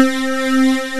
FUNK C5.wav